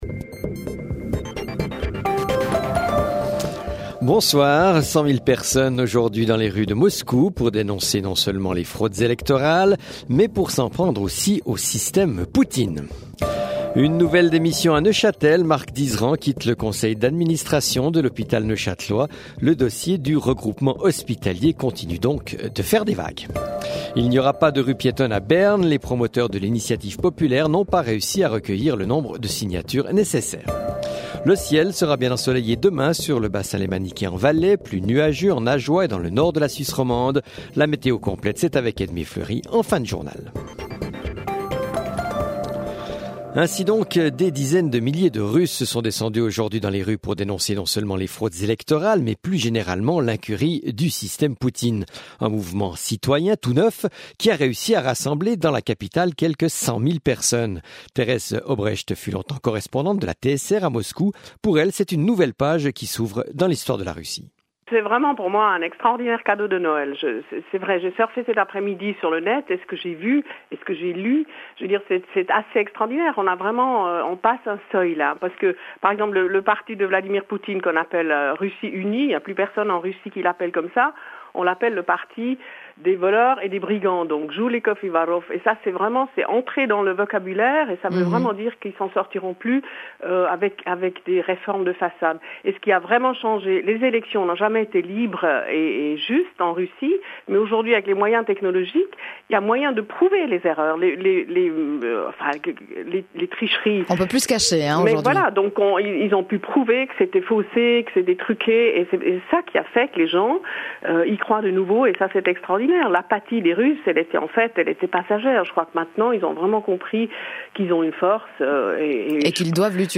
Chaque soir, la rédaction vous offre une synthèse de l’actualité du jour et des dernières informations politiques, économiques et sportives d’ici et d’ailleurs